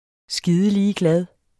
Udtale [ ˈsgiːðəˈliːəˌglað ]